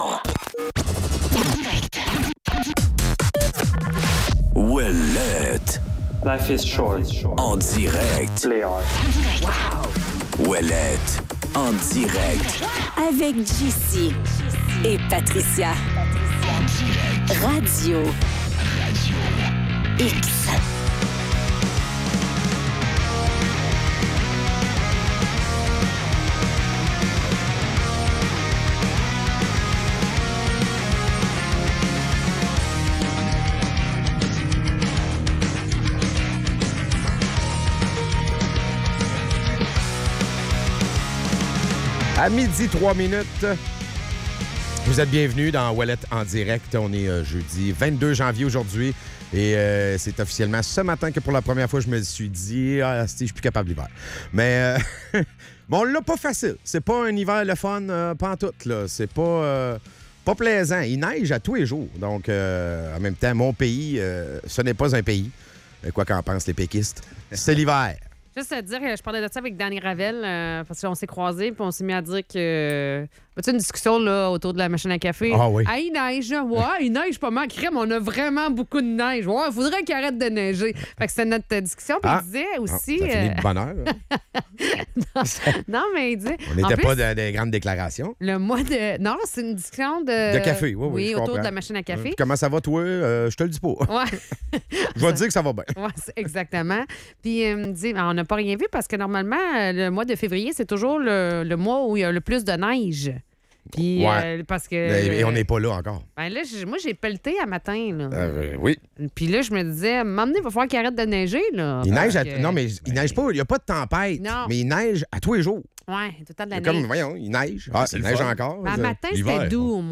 La discussion tourne autour des préoccupations concernant le premier ministre et ses intérêts personnels, notamment en lien avec la course à la chefferie de la CAQ. Les animateurs abordent les enjeux politiques, la stratégie de François Legault pour éviter des conflits internes et les implications des récents accords internationaux, notamment avec le Groenland et la Chine.